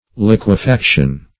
Liquefaction \Liq`ue*fac"tion\ (l[i^]k`w[-e]*f[a^]k"sh[u^]n), n.